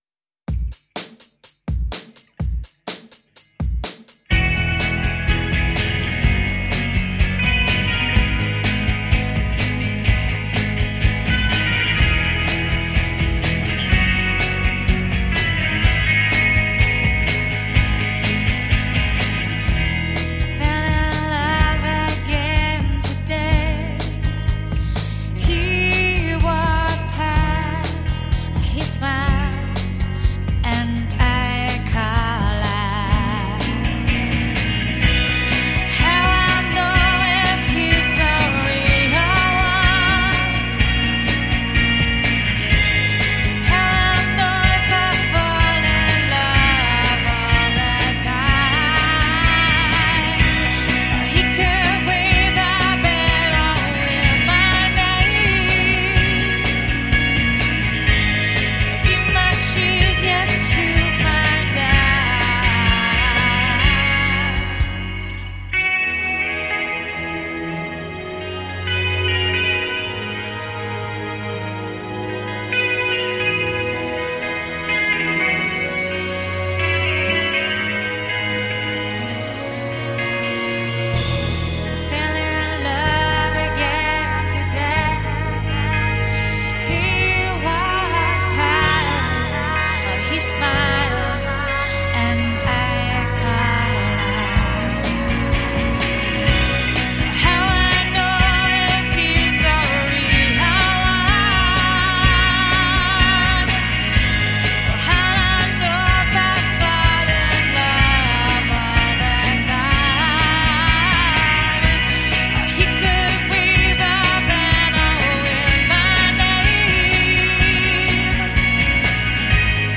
Puccini meets sigur rós.
Tagged as: Electro Rock, Alt Rock, Prog Rock